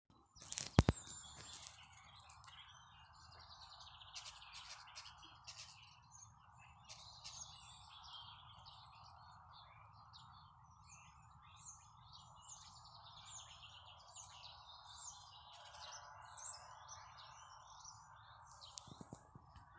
Common Starling, Sturnus vulgaris
Count10 - 12
StatusSinging male in breeding season
NotesStrazdi uzturas pagalma koku zaros.